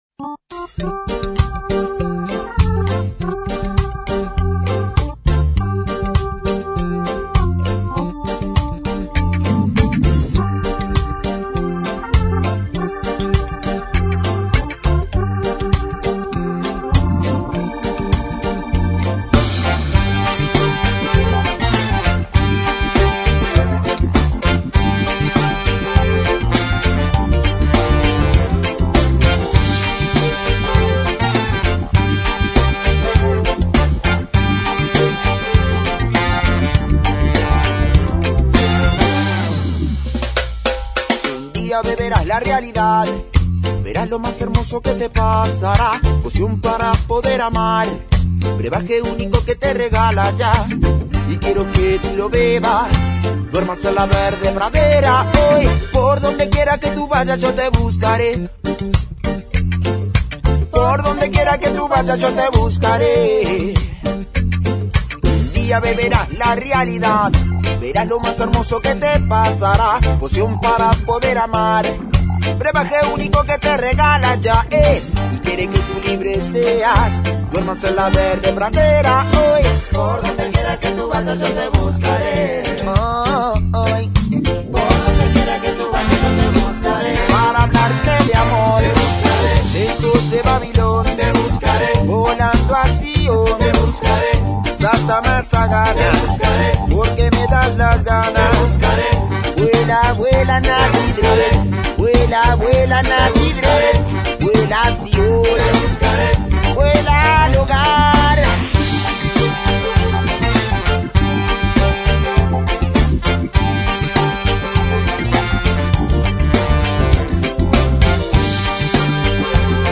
El Congo se hizo presente en las entrevistas centrales de Rock al Rock experience.